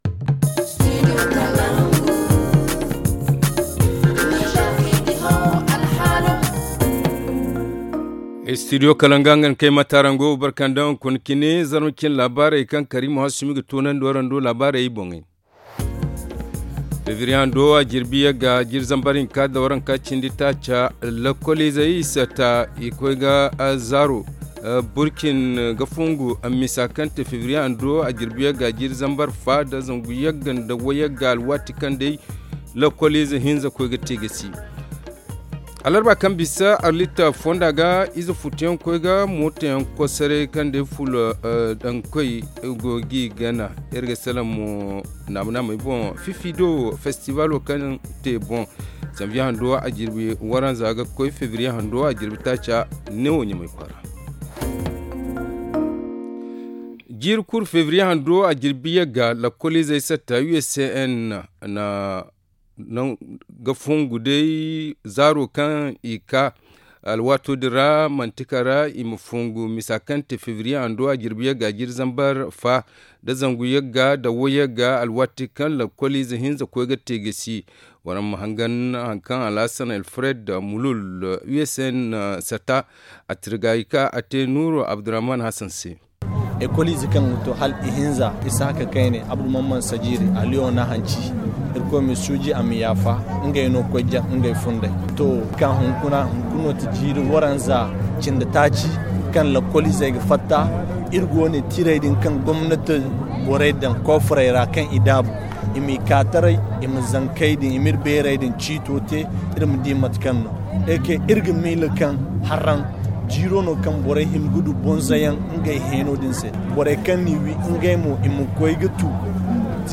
Le Journal du 09 février 2024 - Studio Kalangou - Au rythme du Niger